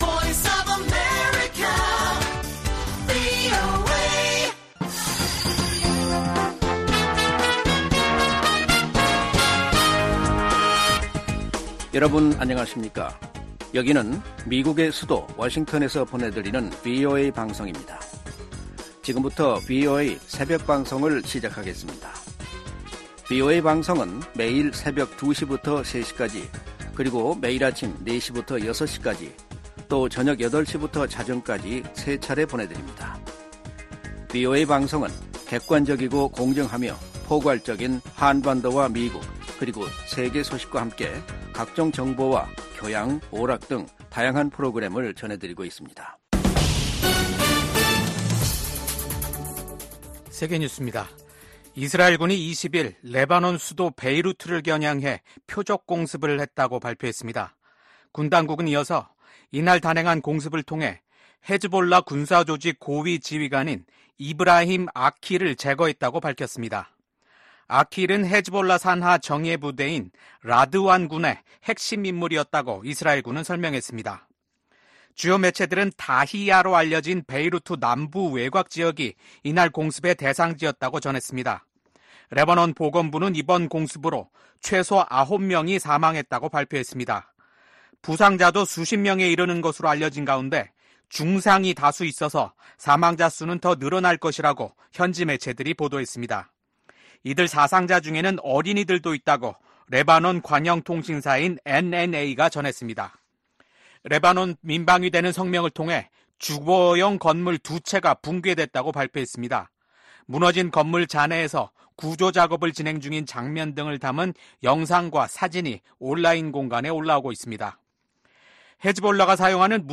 VOA 한국어 '출발 뉴스 쇼', 2024년 9월 21일 방송입니다. 미국 정부가 북-러 간 불법 자금 거래에 관여한 러시아 회사 5곳 및 국적자 1명을 전격 제재했습니다.